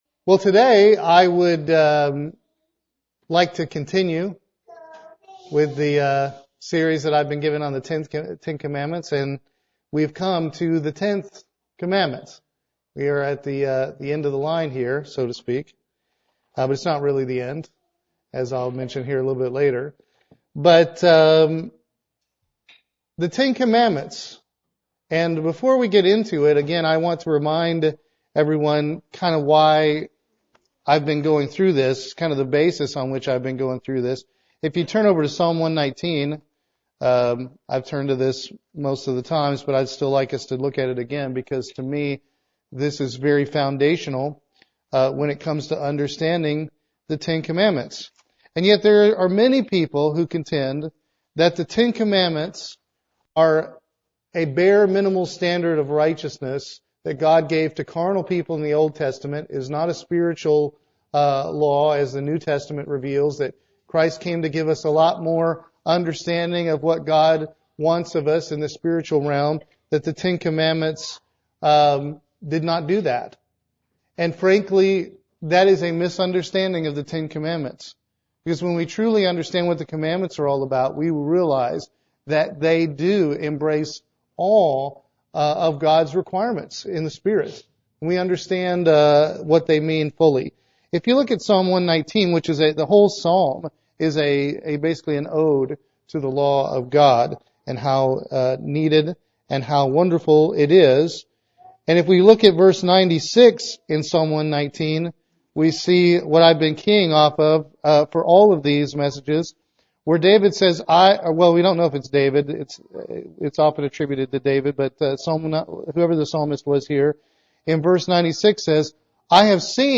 This sermon explores the spiritual applications of the 10th Commandment